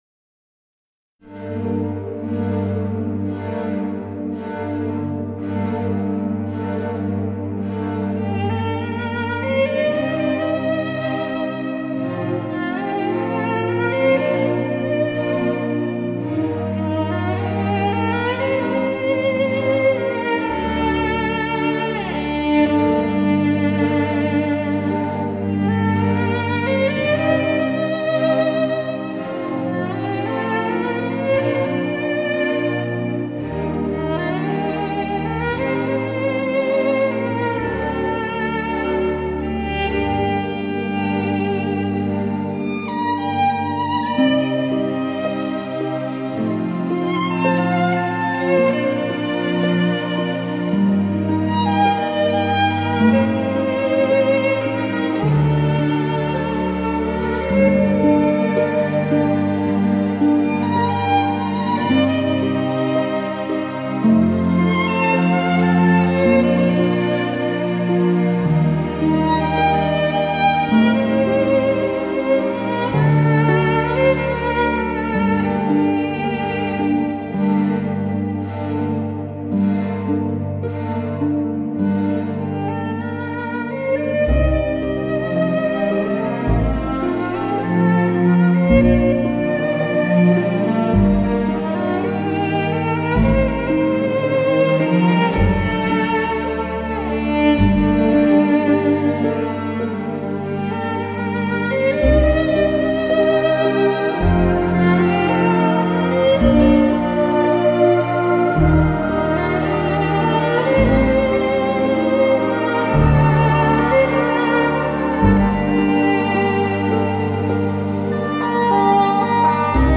演奏